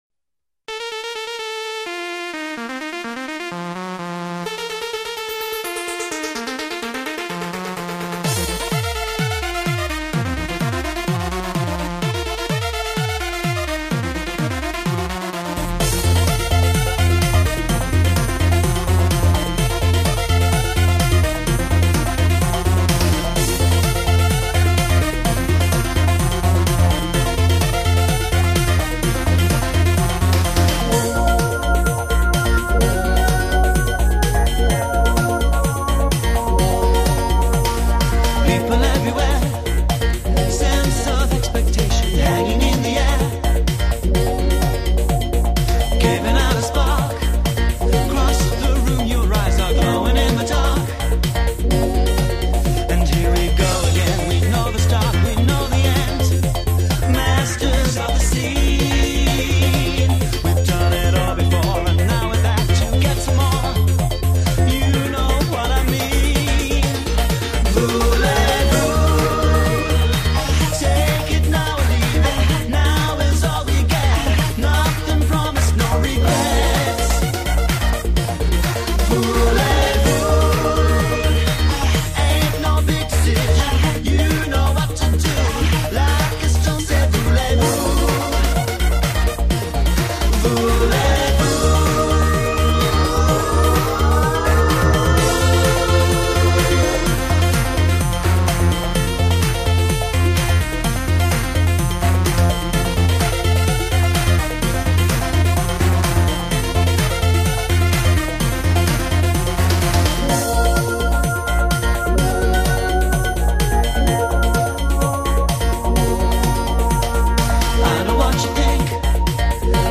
електронна верс≥¤